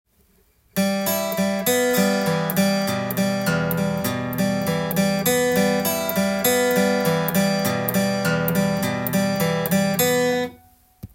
３弦強化
Emでアルペジオをして弾いていきますが
２段目のアルペジオは３弦を必ず挟みながら１弦から６弦まで